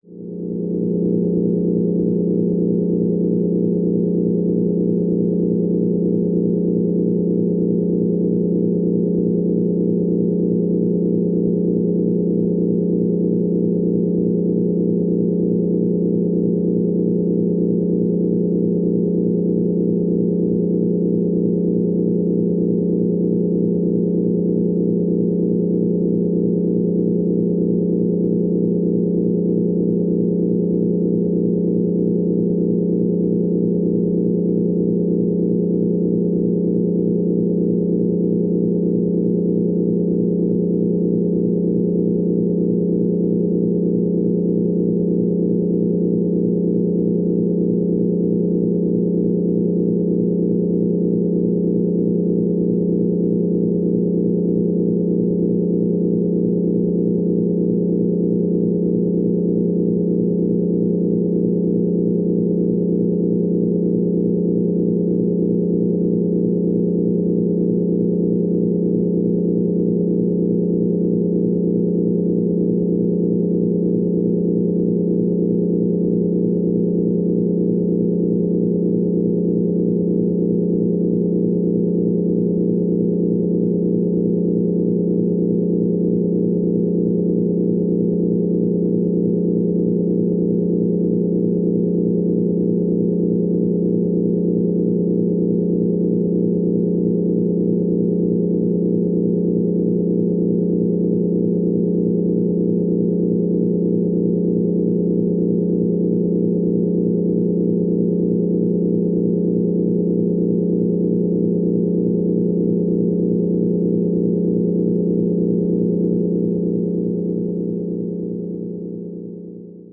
На этой странице собраны звуки работающих реакторов — от глухих гулов до мощных импульсов.
Атмосферный звук за стеклом ядерного реактора